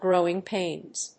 grówing pàins
growing+pains.mp3